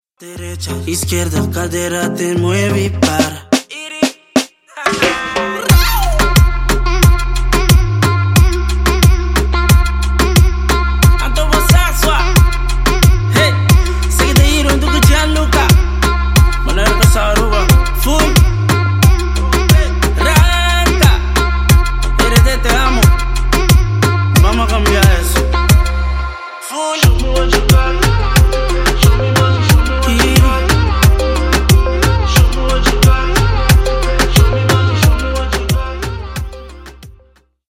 Клубные Рингтоны » # Латинские Рингтоны
Танцевальные Рингтоны